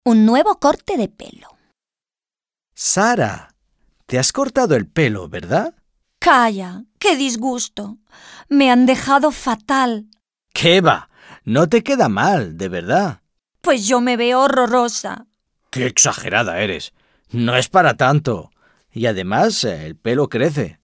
Dialogue - Un nuevo corte de pelo